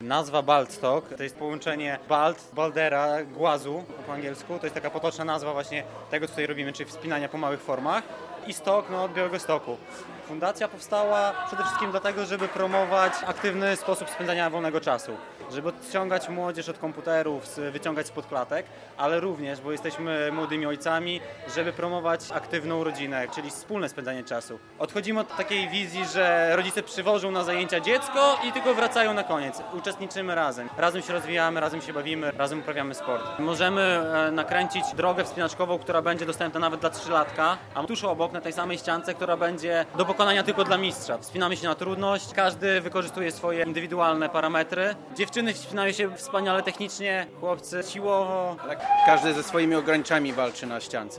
Ścianka wspinaczkowa - relacja